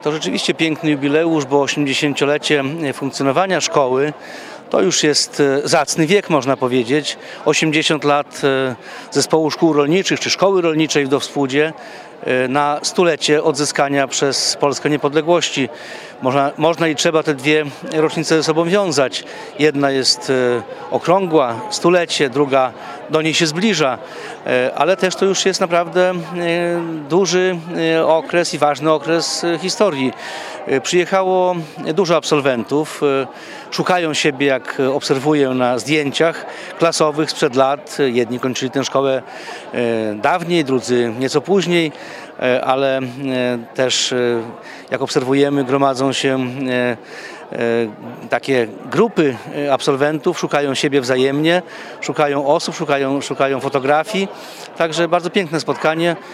Na obchodach święta szkoły obecny był  Jarosław Zieliński, sekretarz stanu w MSWiA.